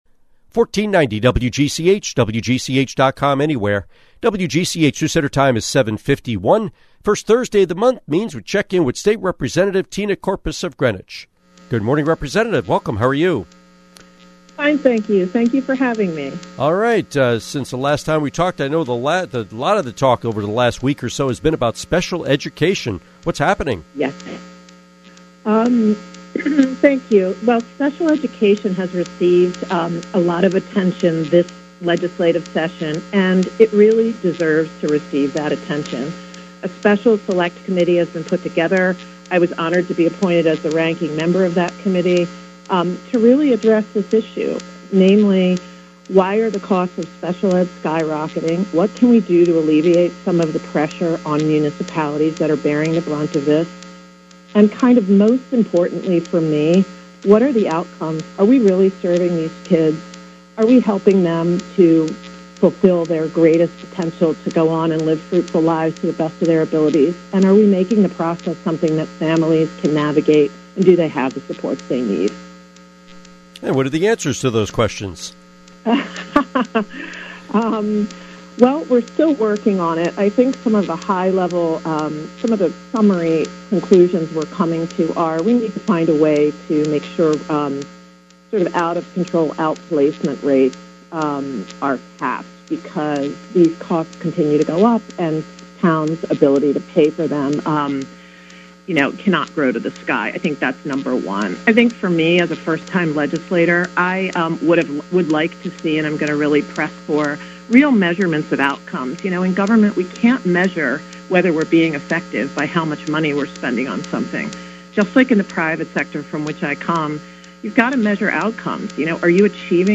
Interview with State Representative Tina Courpas